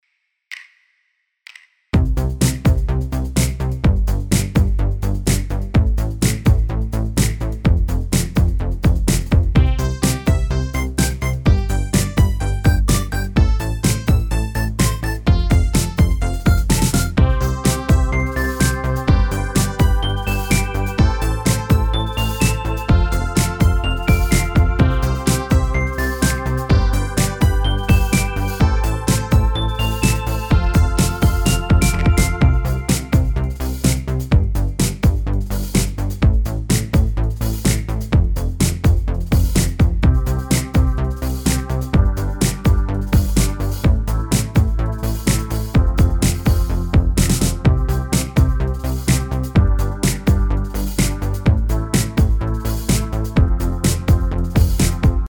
Feine Synthie-Sounds, sehr 80er stilecht. Die Akkorde stimmen auch schonmal.